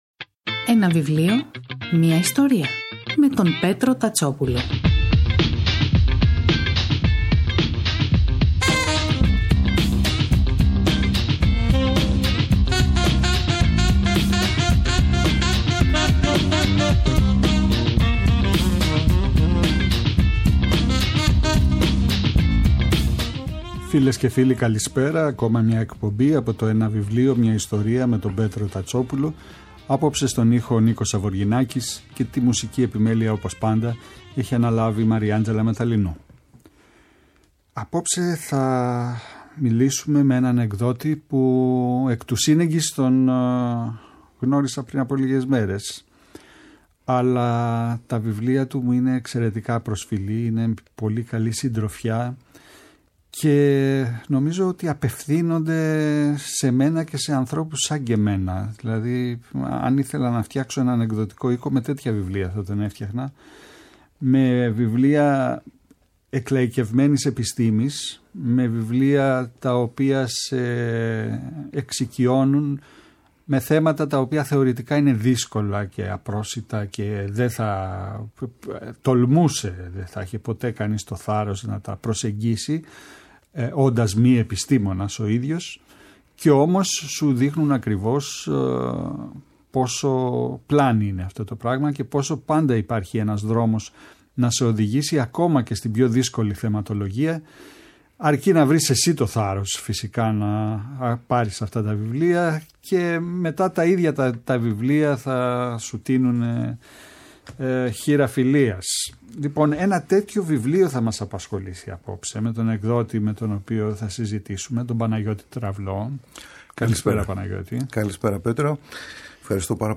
(Από την παρουσίαση στο οπισθόφυλλο του βιβλίου Η εκπομπή “Ένα βιβλίο, μια ιστορία” του Πέτρου Τατσόπουλου , κάθε Σάββατο και Κυριακή, στις 5 το απόγευμα στο Πρώτο Πρόγραμμα της Ελληνικής Ραδιοφωνίας παρουσιάζει ένα συγγραφικό έργο, με έμφαση στην τρέχουσα εκδοτική παραγωγή, αλλά και παλαιότερες εκδόσεις.